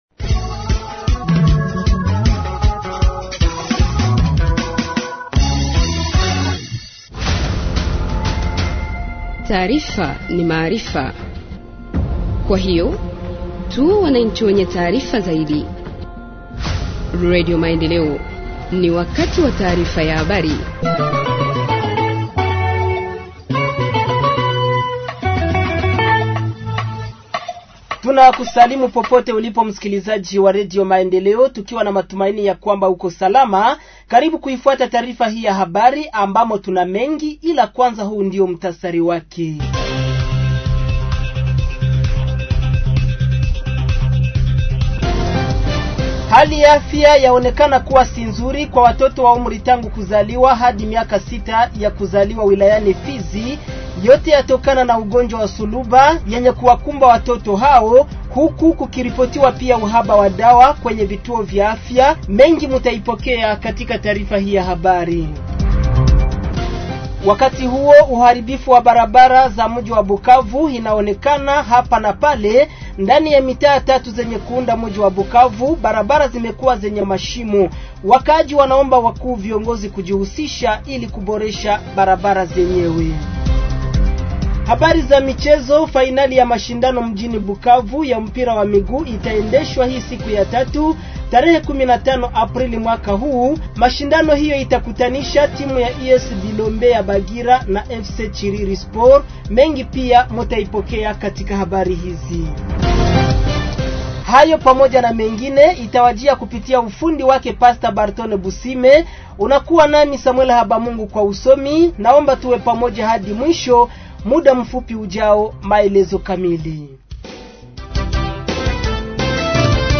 Journal en Swahili du 15 Avril 2026 – Radio Maendeleo